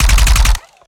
GUNAuto_RPU1 Burst_05_SFRMS_SCIWPNS.wav